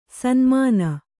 ♪ sanmāna